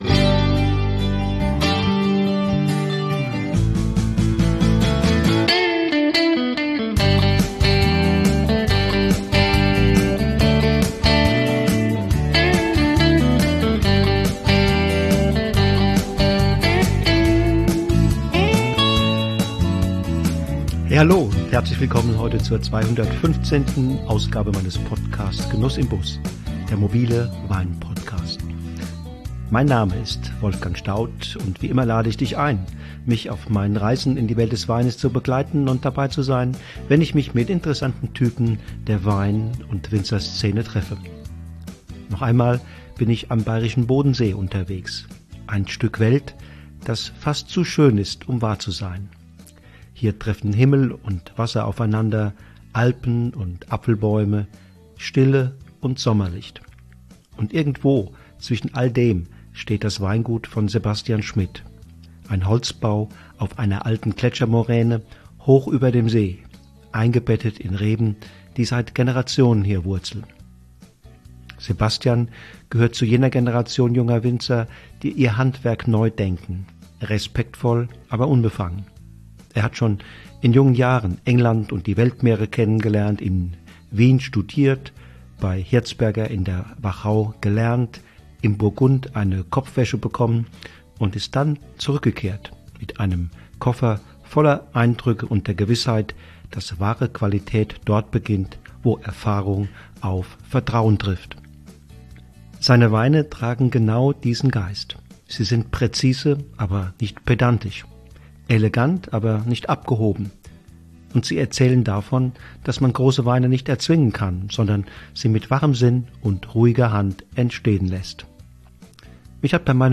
Ein Gespräch über Herkunft, Haltung und das Glück, angekommen zu sein.